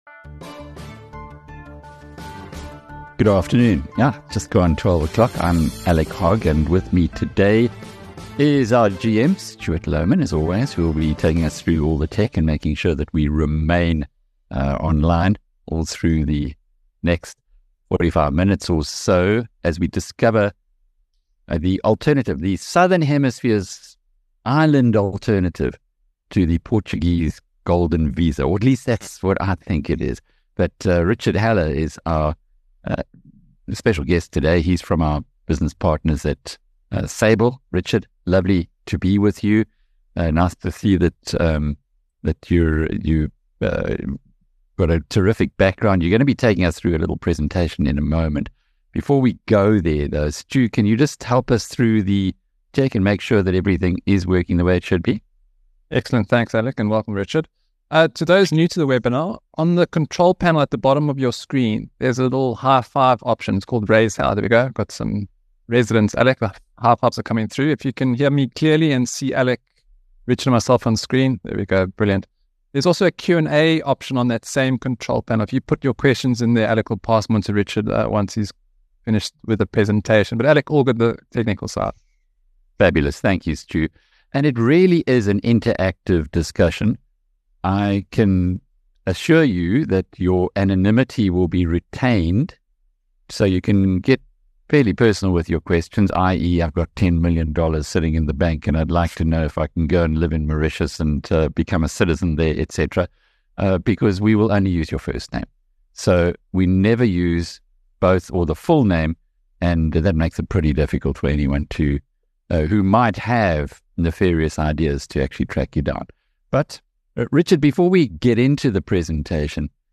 4 Sep Webinar: Discovering Mauritius' residency-by-investment program: Flexibility and lifestyle insights
The session ended with a Q&A on detailed queries about living and investing in Mauritius.